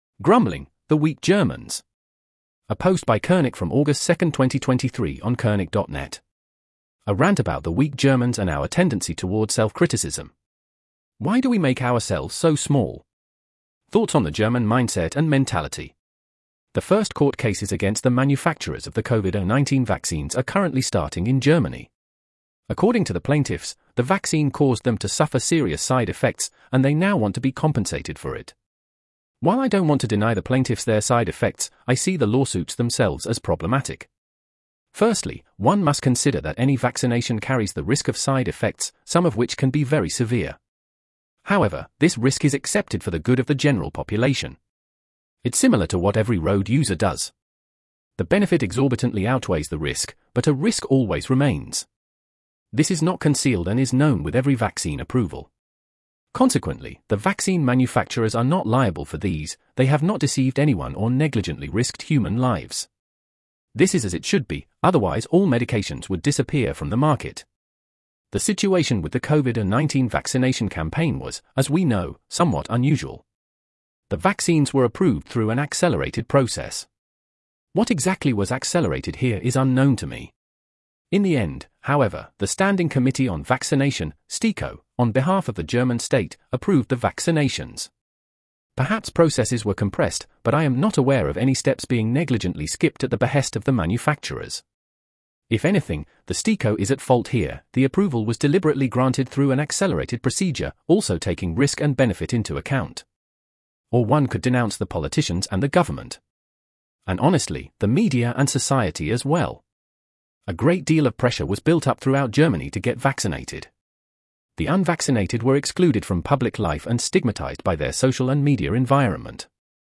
Category: Rant